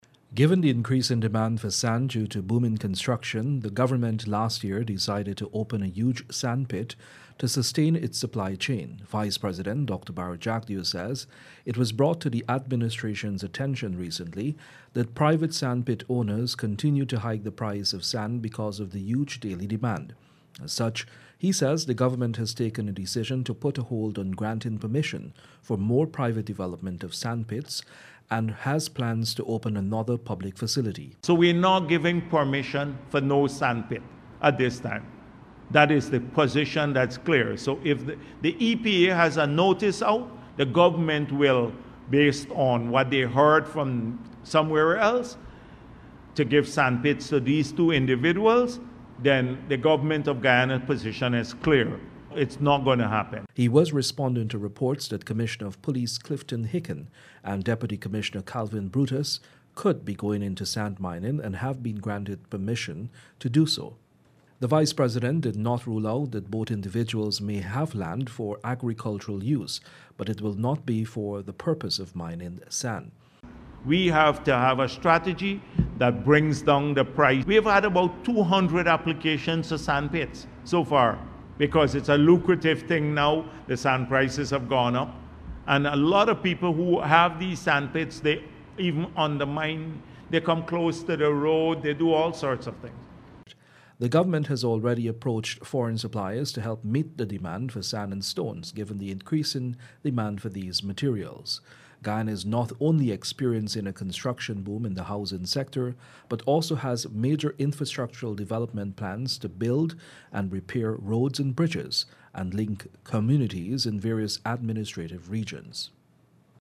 Reporting for NCN News